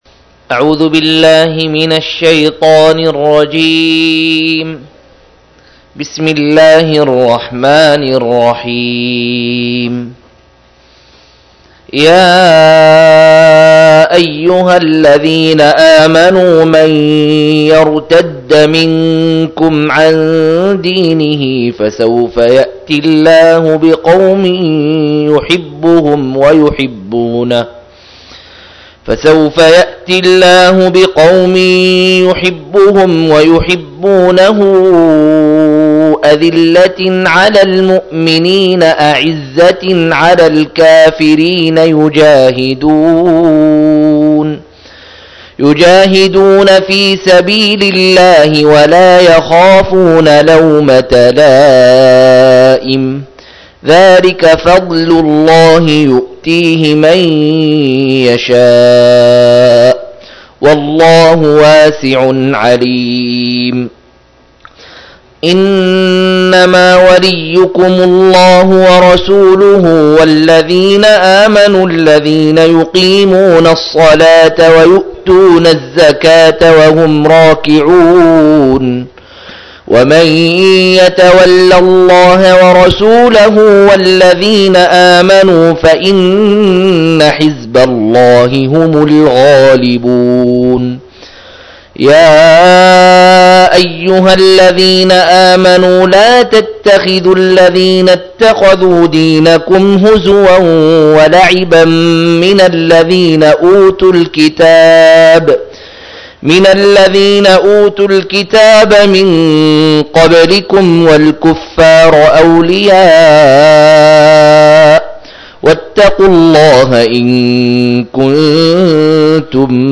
118- عمدة التفسير عن الحافظ ابن كثير رحمه الله للعلامة أحمد شاكر رحمه الله – قراءة وتعليق –